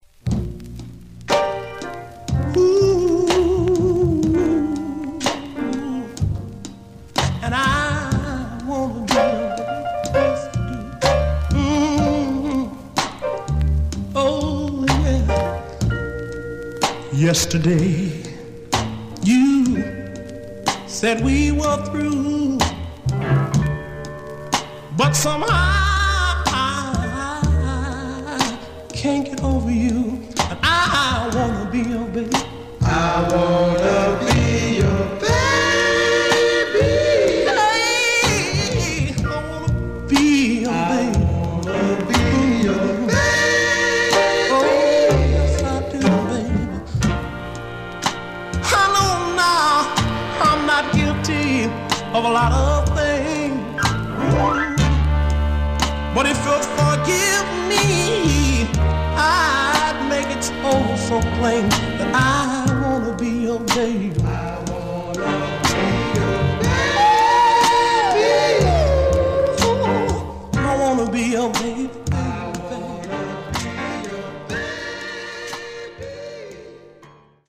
Much surface noise/wear
Mono
Soul